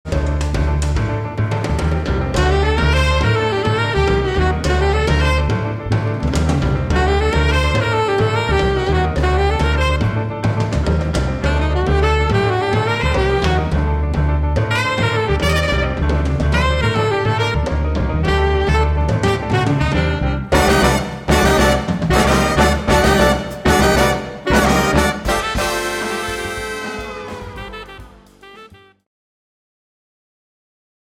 che suona con tratti arabeggianti.